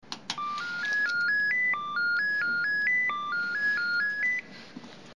Звуки домофона
Звук открытия двери домофона